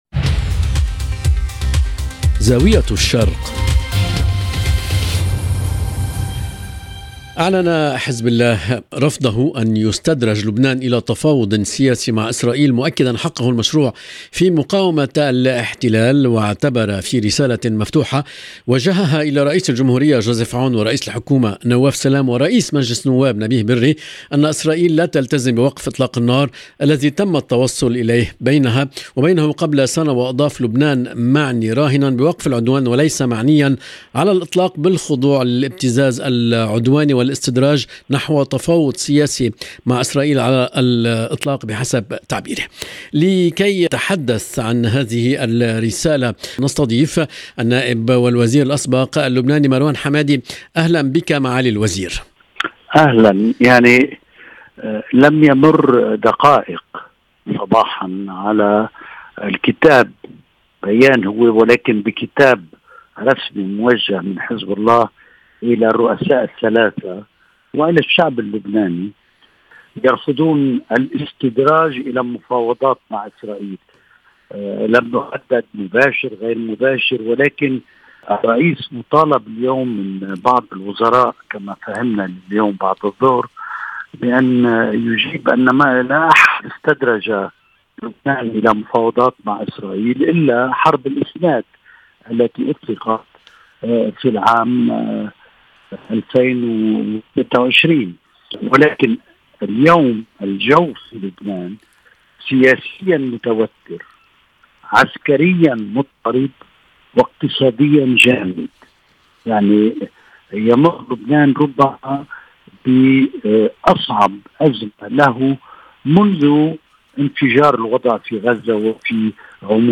النائب والوزير الأسبق مروان حماده، يجيب عن هذه الأسئلة، محلّلًا أبعاد المشهدين السياسي والعسكري في لبنان والمنطقة.